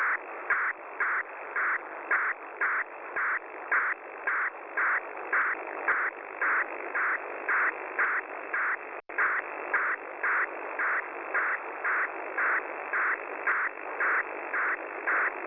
arq сигнал на 14117кГц